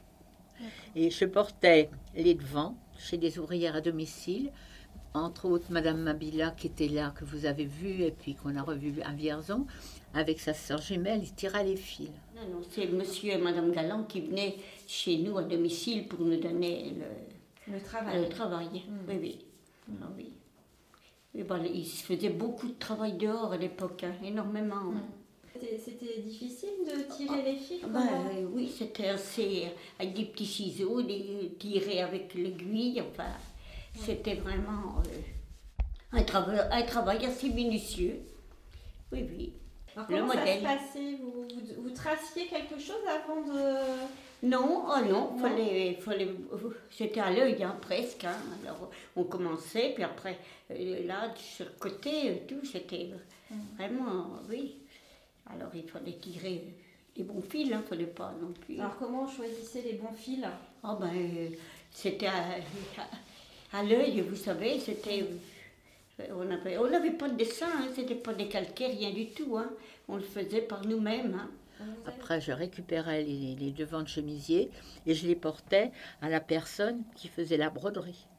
Cet entretien a été réalisé dans le cadre de la recherche MEMOVIV menée par une équipe de chercheurs en sociologie de l'Université de Tours (CITERES/CETU ETIcS), avec le soutien des archives départementales du Cher et de CICLIC, L'agence du Centre-Val de Loire pour le livre, l'image et la culture numérique.